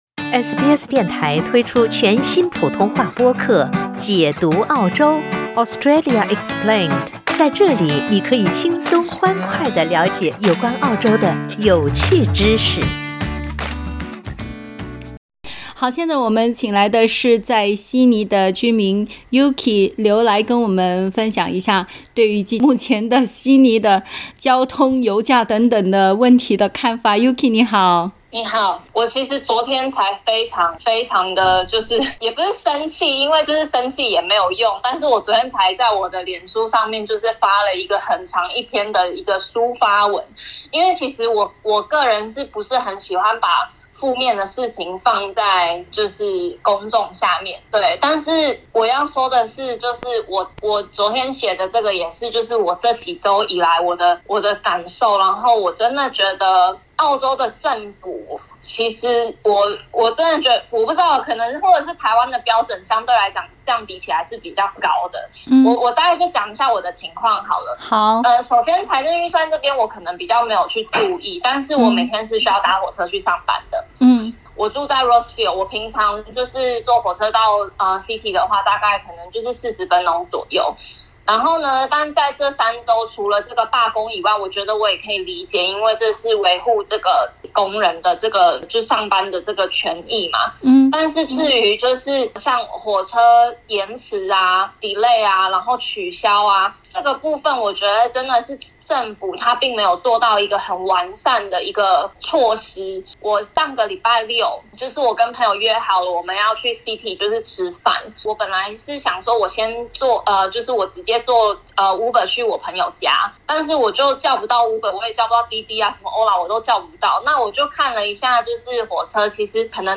（点击图片收听报道收听完整采访）